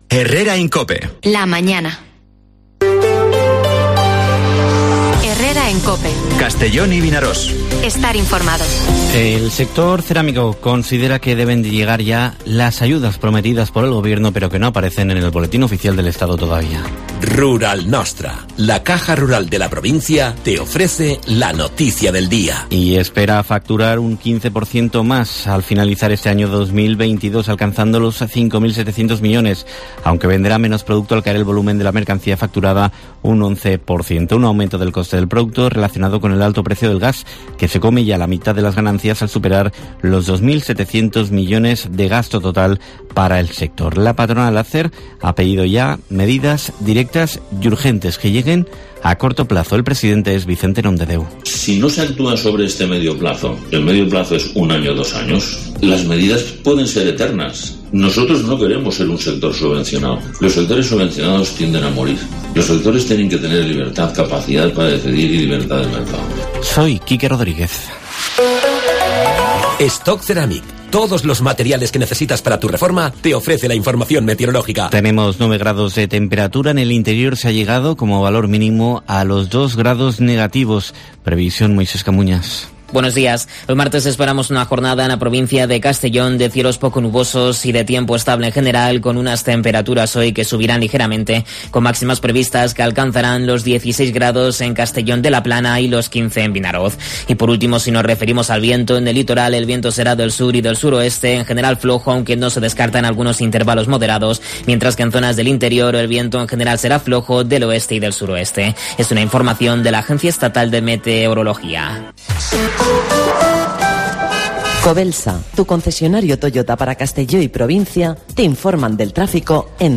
Informativo Herrera en COPE en la provincia de Castellón (20/12/2022)